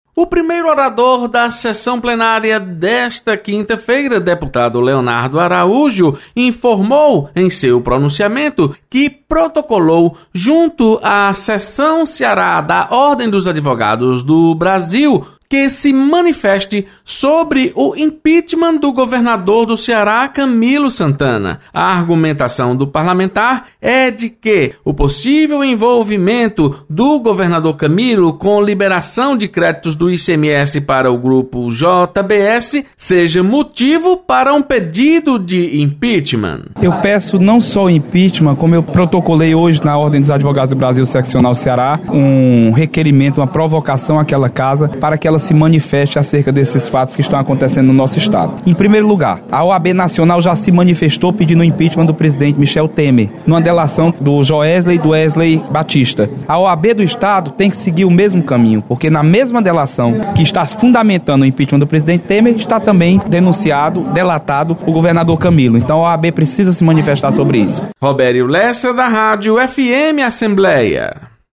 Deputado Leonardo Araújo defende impeachment do governador Camilo Santana. Repórter